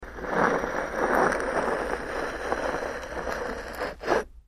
Scrape, Stone
StoneScrapesSmooth PE442101
Stone Scrapes; Smooth Cement.